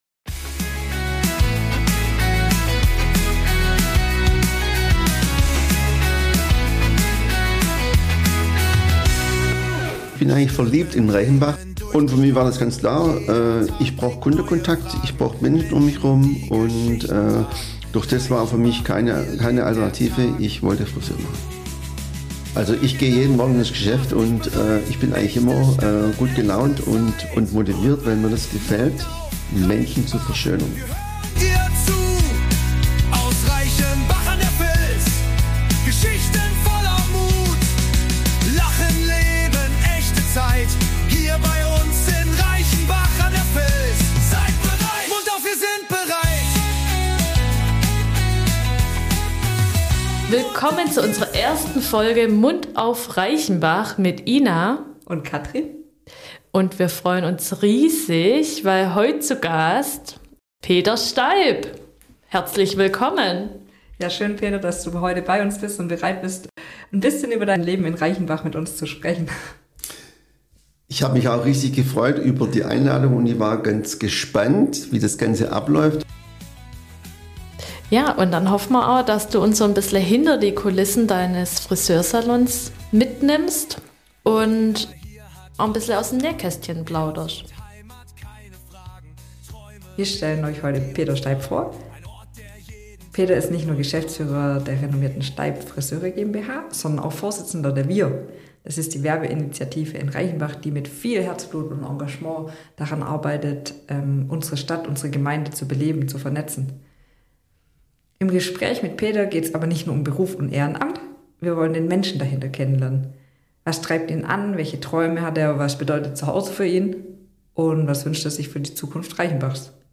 Ein herzliches, humorvolles Gespräch über Heimatgefühl, besondere Erinnerungen und Träume erwarten euch.